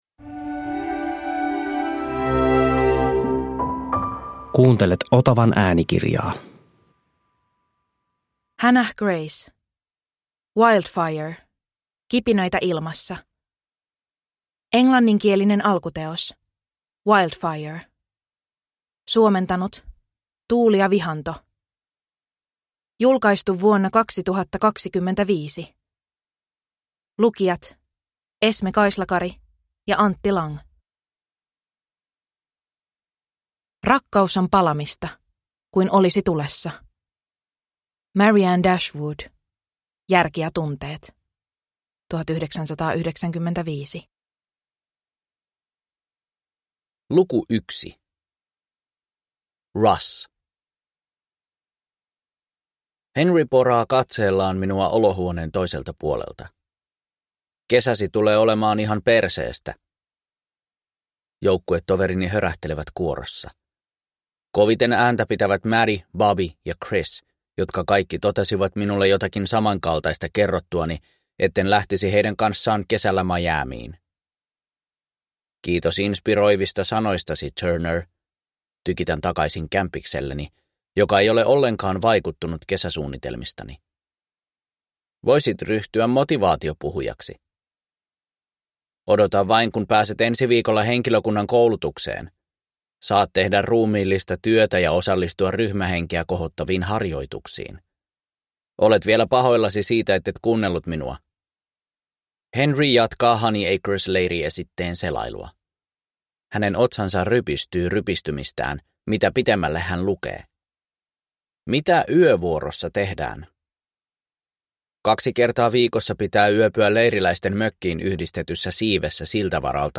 Wildfire – Kipinöitä ilmassa (ljudbok) av Hannah Grace